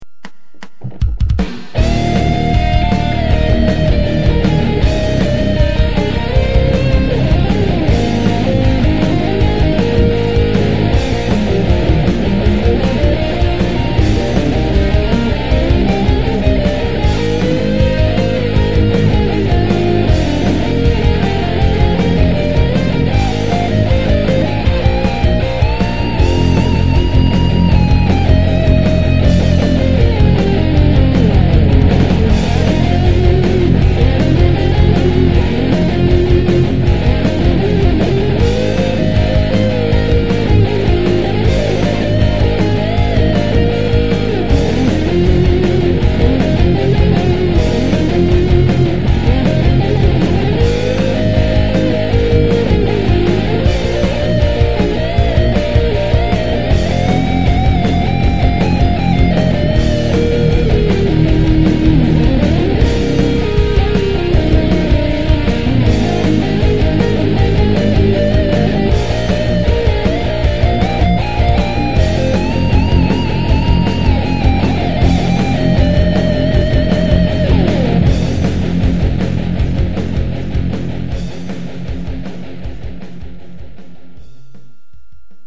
その分サイズは大きくなりますが、音質は結構いいと思います。
なんだか映画”トップガン”を彷彿とさせる、ミドルテンポの曲です（簡単♪）。
エフェクターもいろいろ実験して大型アンプをシュミレートしてみました（でもライン取り）。
バッキングギター&リードギター
ドラム&ベース：SC-88Pro